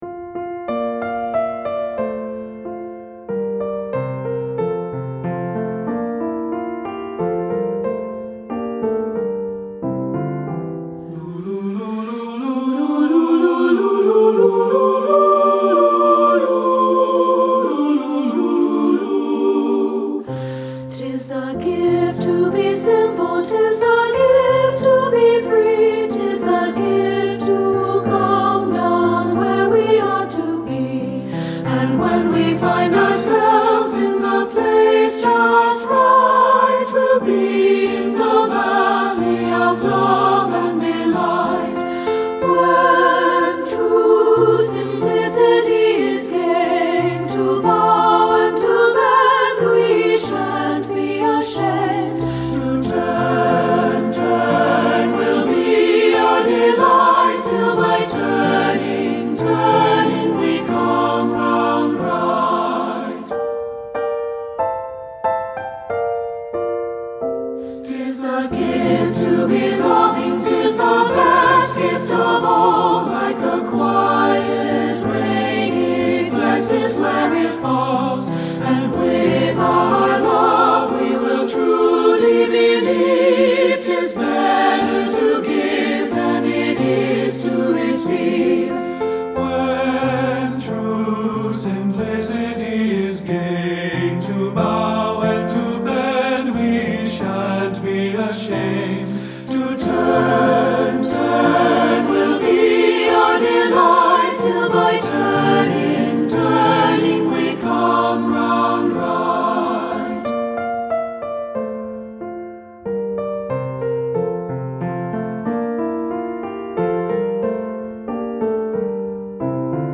• Piano
Studio Recording
unison passages
optional a cappella sections
Ensemble: Three-part Mixed Chorus
Accompanied: Accompanied Chorus